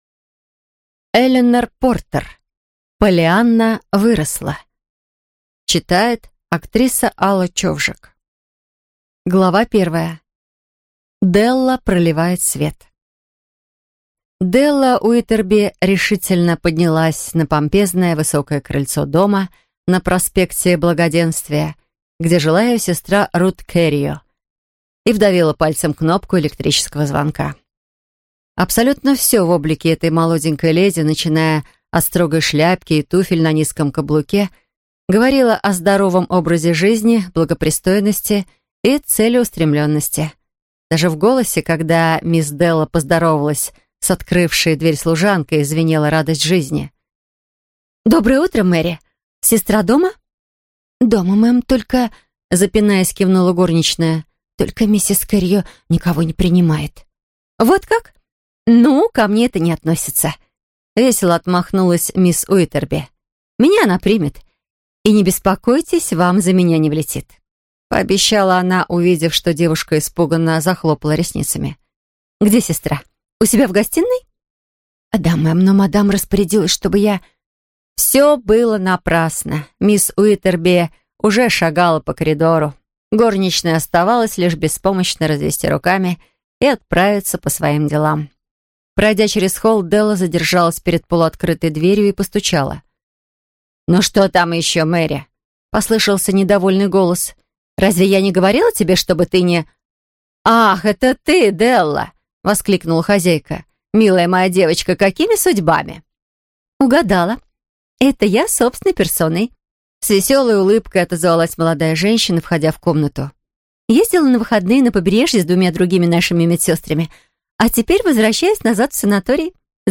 Aудиокнига Поллианна выросла